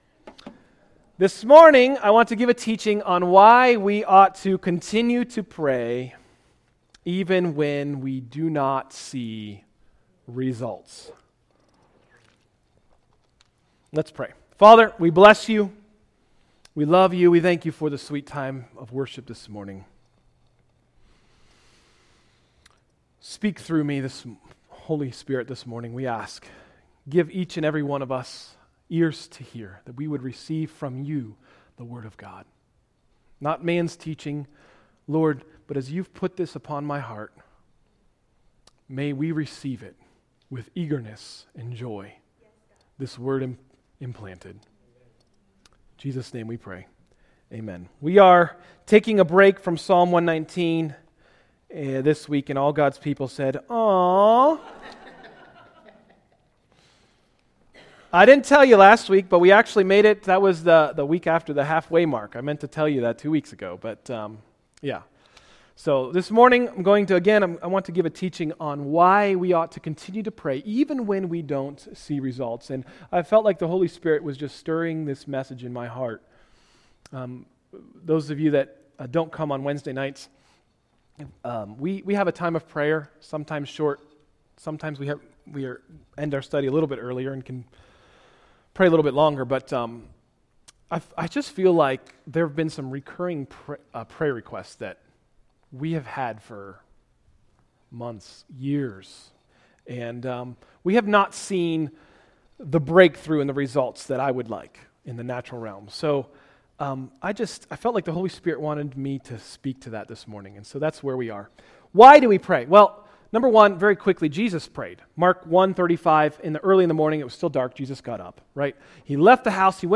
Message: “Don’t lose Heart” – Tried Stone Christian Center